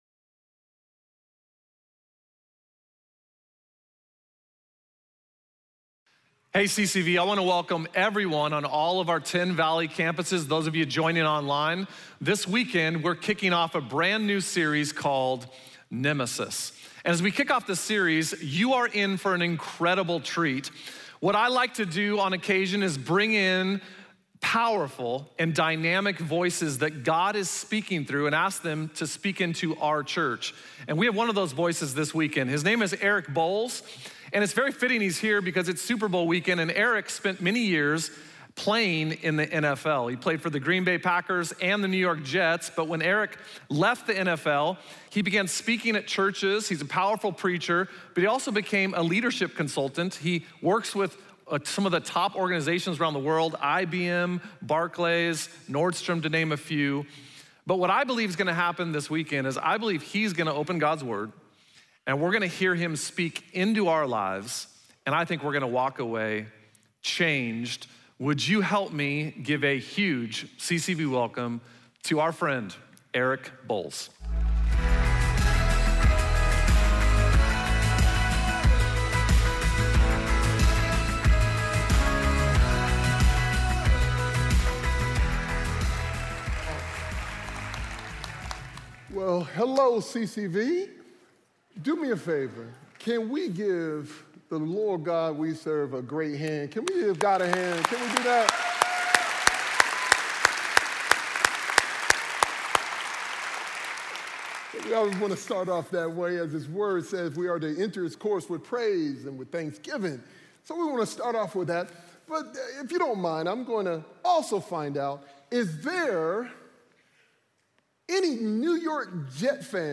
Message Only Full Service Scarcity vs. Abundance Guest Speaker Feb 1, 2020 Have you ever felt like the odds were stacked against you?